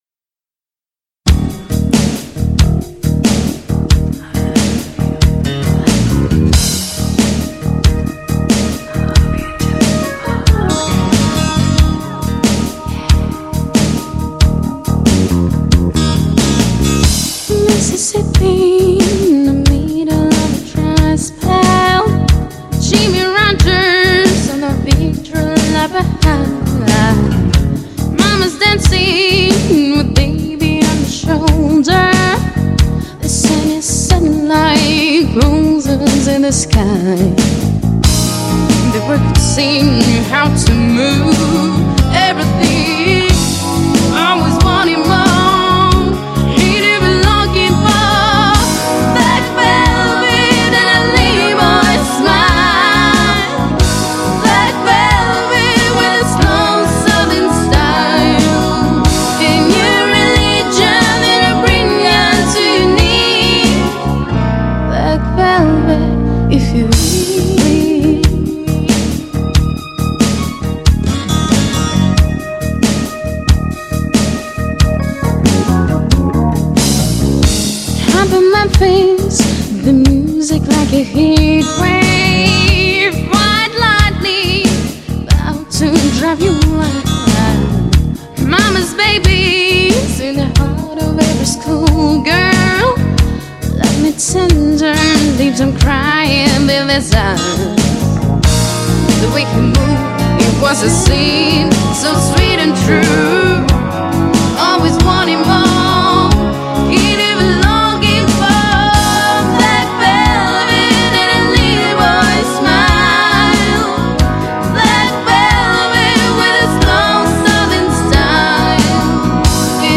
минусовка, правда, почти один в один.